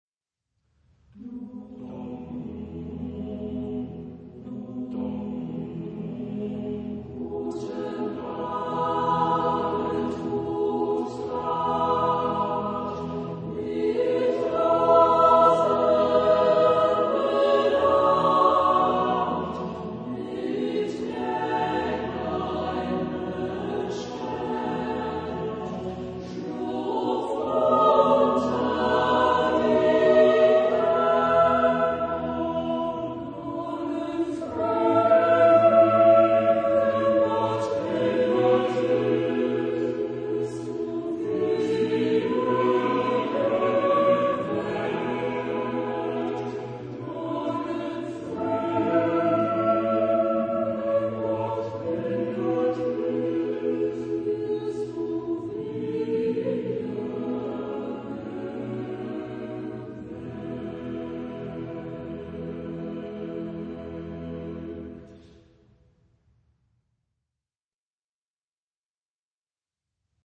Genre-Style-Form: Partsong ; Lullaby ; Folk music ; Sacred
Type of Choir: SSAATBBB  (8 mixed voices )
Tonality: E major